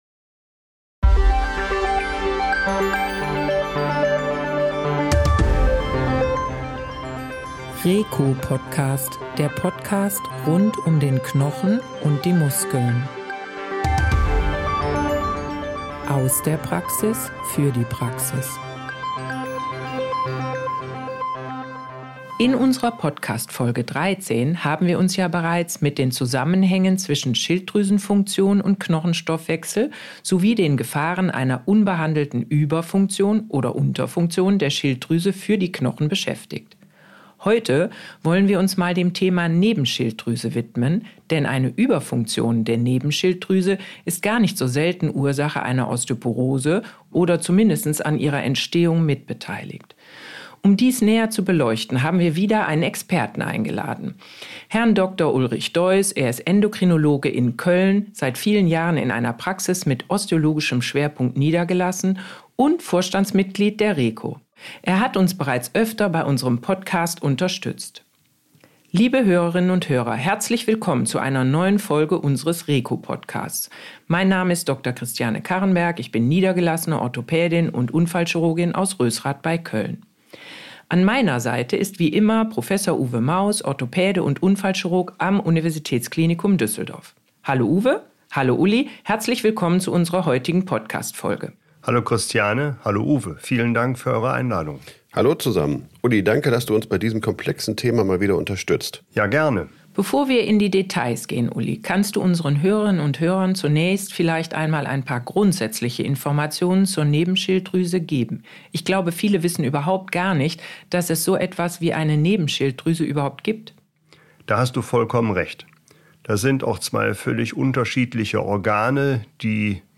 Beschreibung vor 9 Monaten Ein oft übersehenen Thema: die Überfunktion der Nebenschilddrüsen (Hyperparathyreoidismus). Denn sie kann nicht nur eine Osteoporose auslösen, sondern auch erheblich zu ihrer Entstehung beitragen. Im Gespräch mit dem Endokrinologe